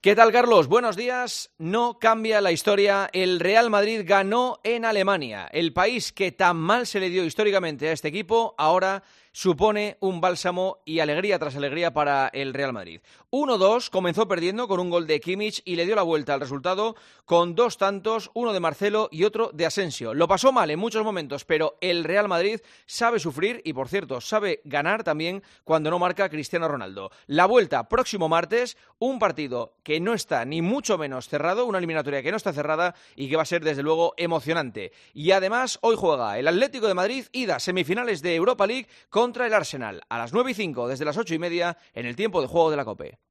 Escucha el análisis de la actualidad deportiva de mano del director de 'El Partidazo' de COPE